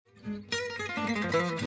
Simple, efficace et injouable à son allure à lui
D'harmonie, c'est une substitution tritonique
planE7AngelloDebarre.mp3